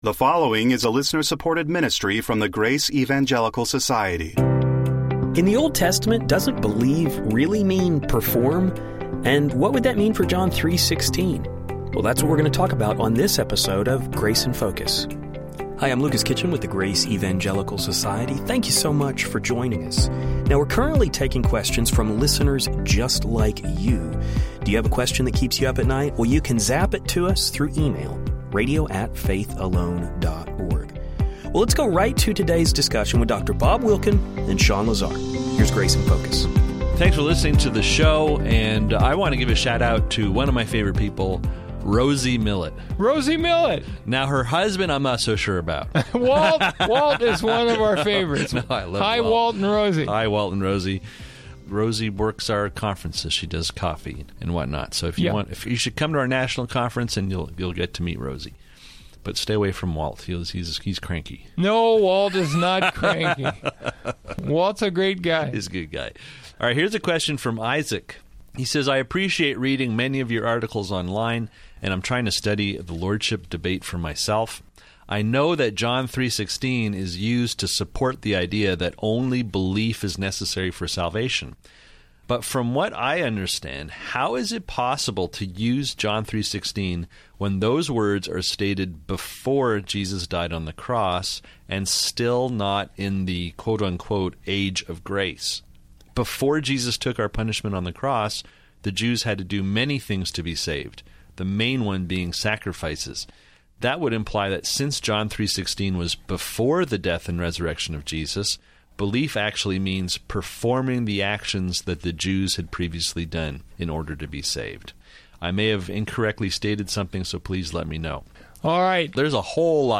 Does the word “believe” include the idea of performance and works? Today on Grace in Focus radio, we will be addressing these and many more questions.